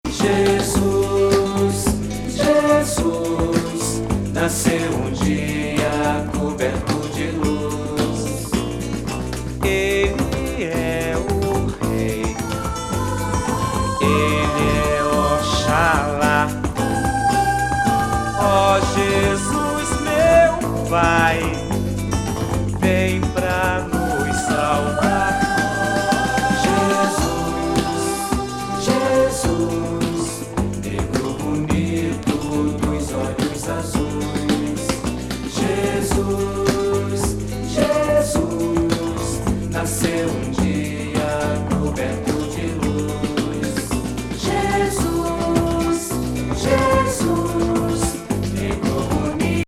B面はサウダージ溢れる叙情的ナンバー